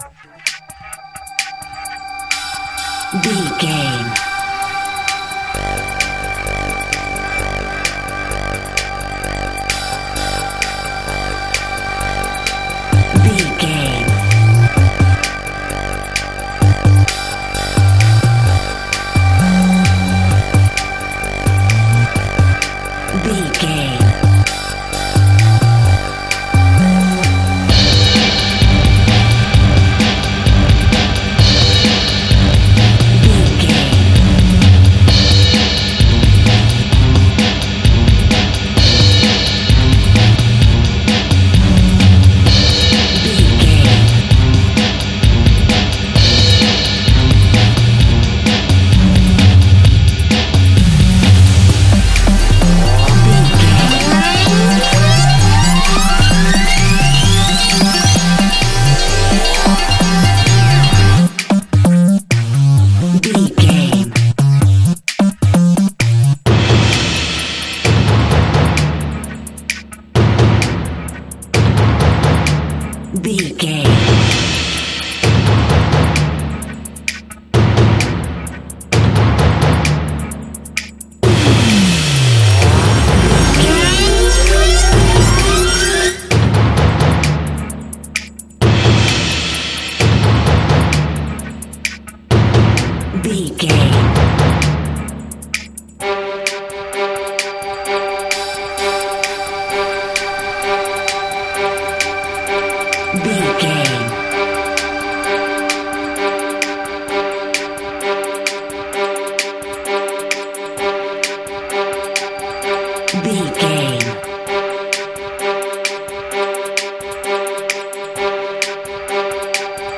Epic / Action
Fast paced
Aeolian/Minor
aggressive
powerful
dark
funky
groovy
futuristic
driving
energetic
synthesizers
drums
bass guitar
Finger Clicks
strings